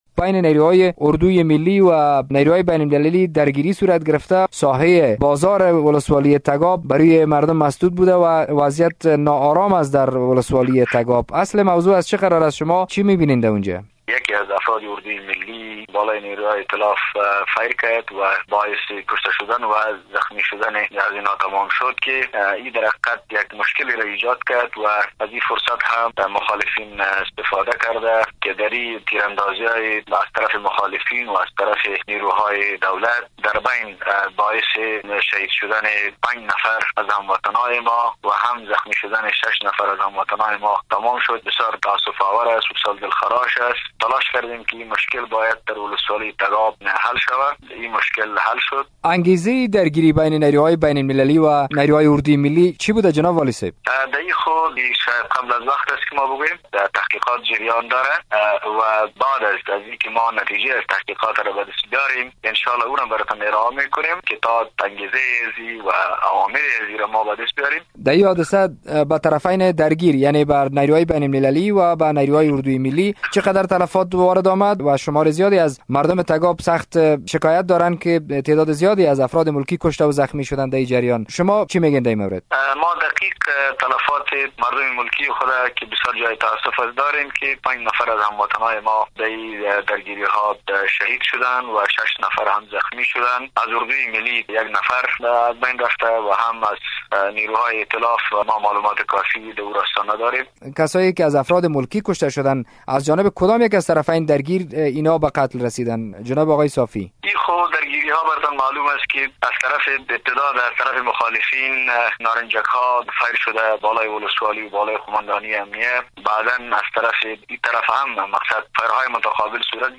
مصاحبه با محراب الدین صافی والی کاپیسا در مورد درگیری اخیر در آن ولایت